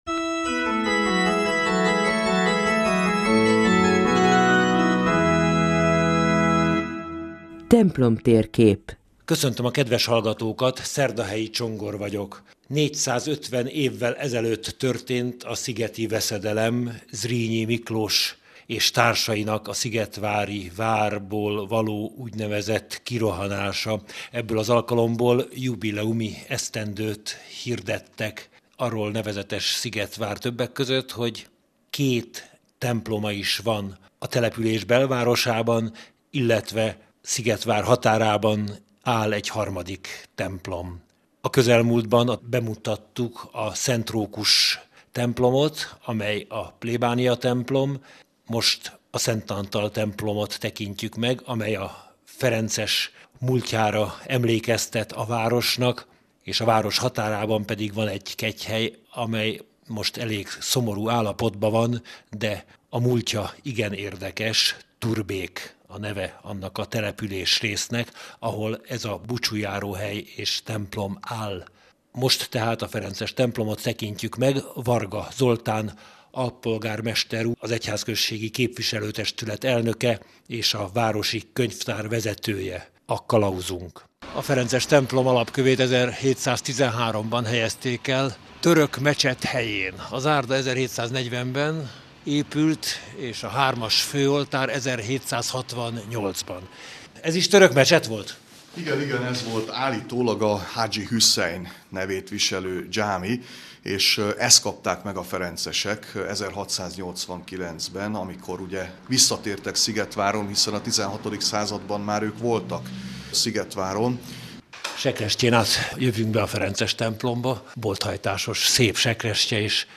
A szigetvári ferences templomot és rendházat Varga Zoltán mutatja be, aki a város alpolgármestere, a városi könyvtár vezetője és a katolikus egyházközség képviselő testületének elnöke. A műsor a Katolikus Rádióban március 13-án hangzott el, Templom-tér-kép címmel.